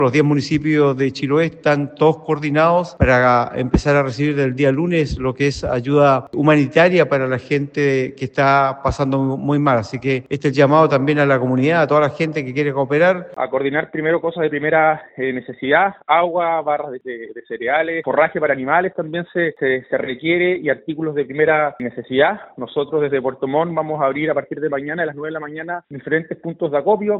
En ese contexto, el presidente de la organización que agrupa a los diez municipios de Chiloé, René Garcés, y el alcalde de Puerto Montt, Rodrigo Wainraihgt, detallando que están recolectando agua, barras de cereales y forraje para animales, sumado a artículos de primera necesidad.